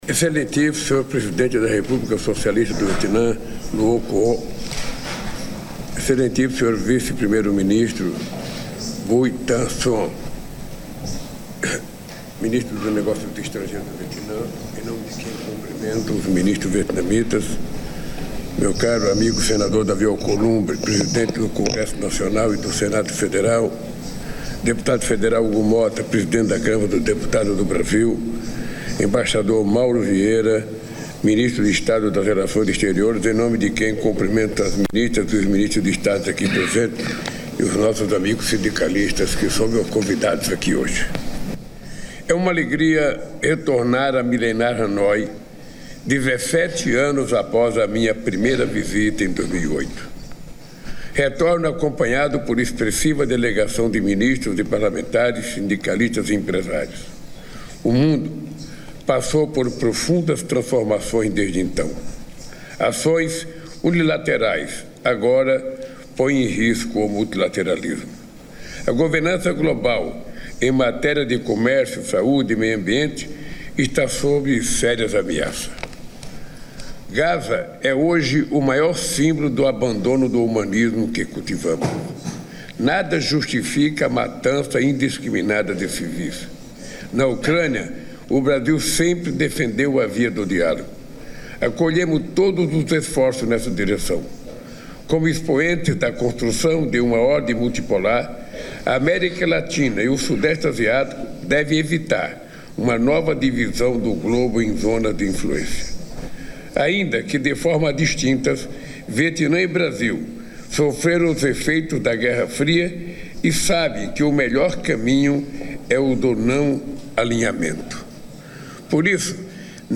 Íntegra da declaração à imprensa do presidente da República, Luiz Inácio Lula da Silva, após encontro com o presidente do Vietnã, Luong Cuong, nesta sexta-feira (28), no Palácio Presidencial, em Hanói (Vietnã).